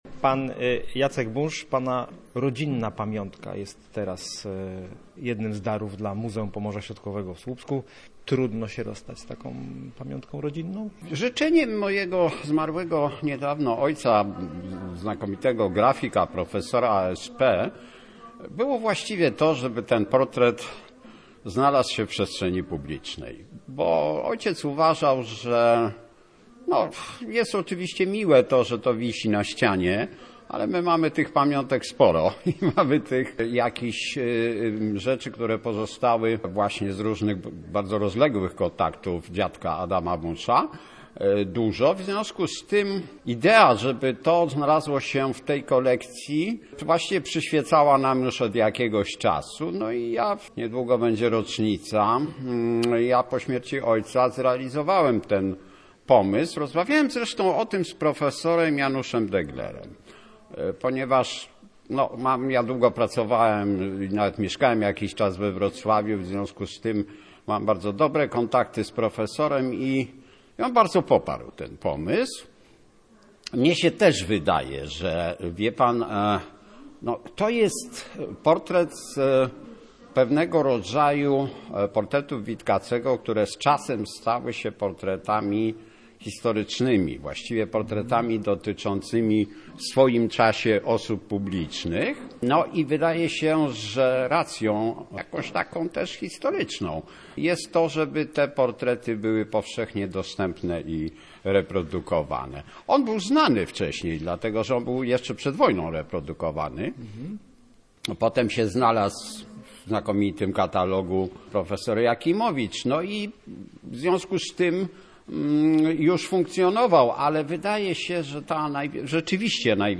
Muzeum Pomorza Środkowego w Słupsku ma największą w Polsce kolekcję prac Witkacego obejmująca około trzystu obrazów, szkiców czy rysunków. Posłuchaj relacji naszego reportera: https